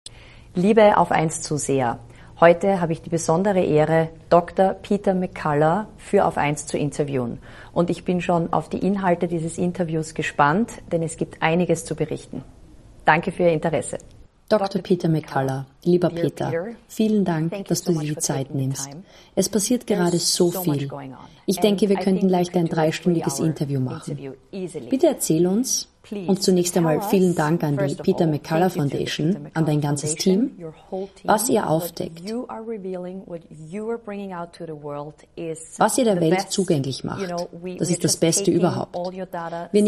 diesem ausführlich Gespräch